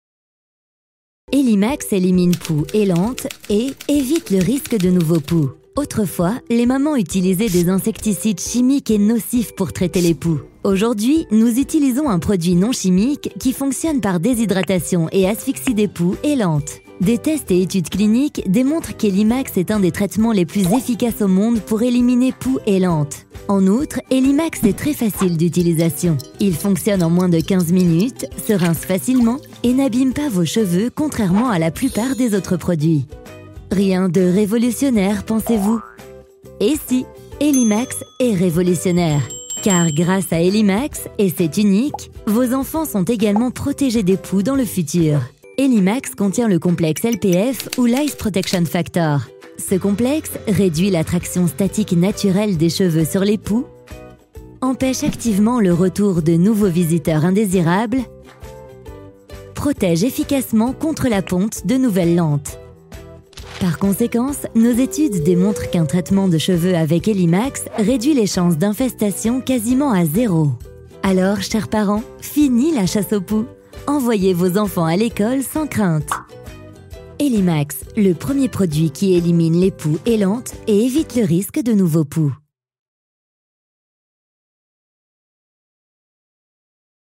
Native Voice Samples
Corporate Videos